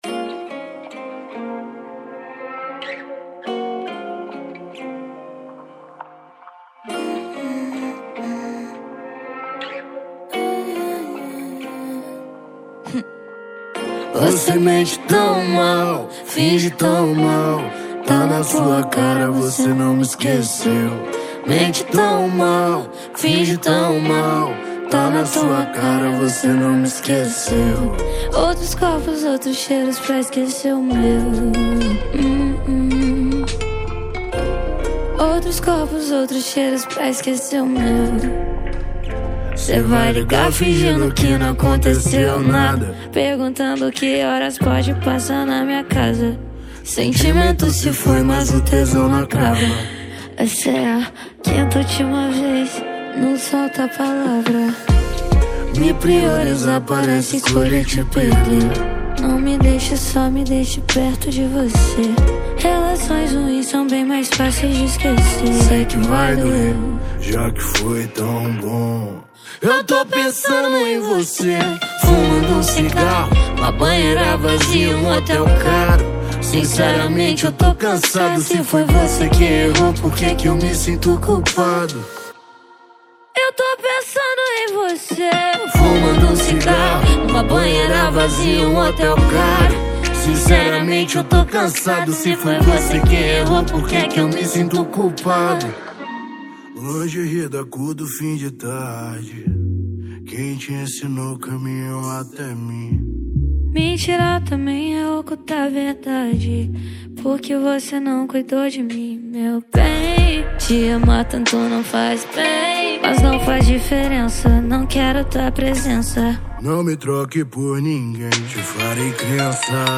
2024-12-21 23:47:41 Gênero: MPB Views